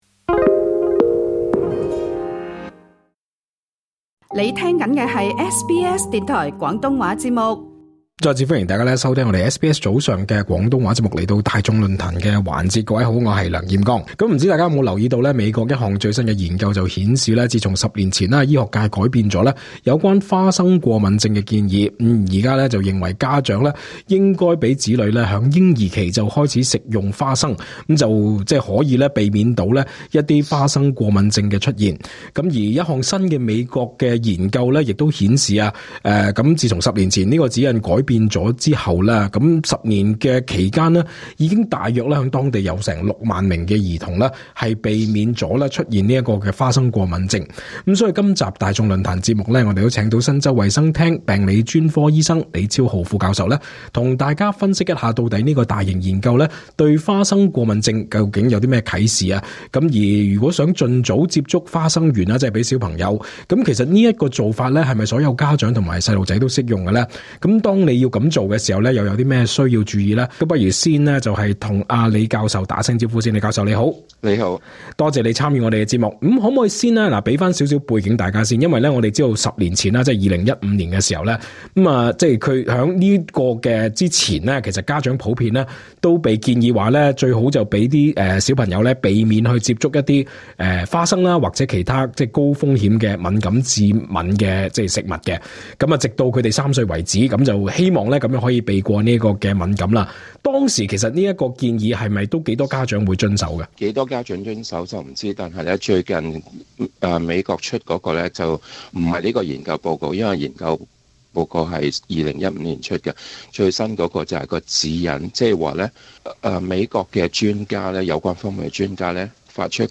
詳盡訪問內容： LISTEN TO 研究：盡早接觸令6萬兒童避過「花生敏感」專家籲須謹慎為之 SBS Chinese 18:05 yue 上月發布的最新美國研究，重檢多個州份兒科診所的電子病歷，發現自 2015 年指引調整以來，幼兒被診斷為花生過敏的比例明顯下降。